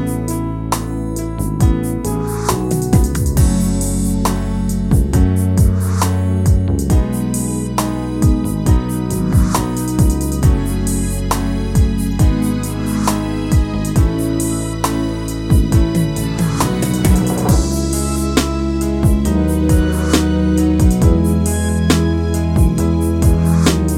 Pop (1990s)